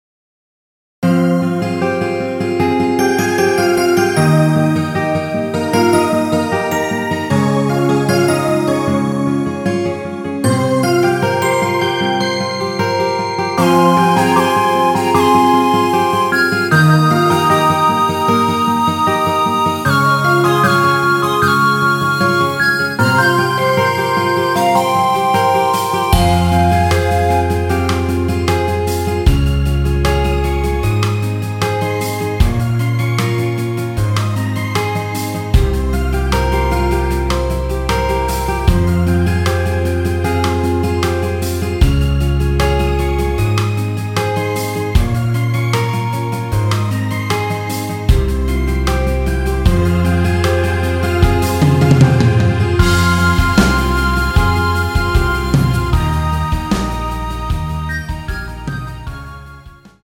원키에서(+2)올린 MR입니다.
Fm
앞부분30초, 뒷부분30초씩 편집해서 올려 드리고 있습니다.
중간에 음이 끈어지고 다시 나오는 이유는